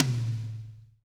-DRY TOM 3-L.wav